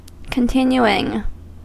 Ääntäminen
Ääntäminen US Haettu sana löytyi näillä lähdekielillä: englanti Käännös 1. continuāns 2. resistēns 3. permanēns 4. pergēns Continuing on sanan continue partisiipin preesens.